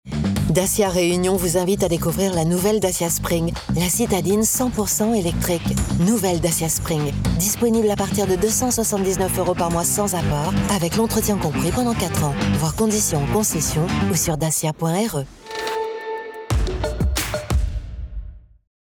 Female
French (Parisienne)
Adult (30-50)
Radio Commercial
1008PUB-Radio-DACIA_Spring.mp3